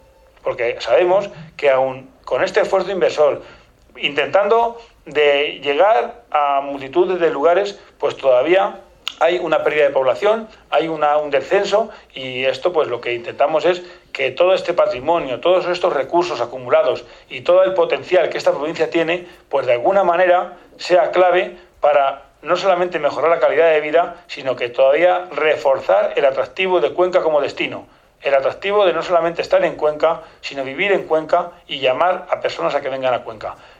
Así lo ha puesto de manifiesto el presidente de la institución provincial durante la rueda de prensa de este jueves para realizar balance de los tres años de legislatura, en la que ha estado acompañado del vicepresidente, Julián Huete, y de los diputados, Francisco Javier Doménech, Paloma García Casado, David Cuesta y Óscar Pinar.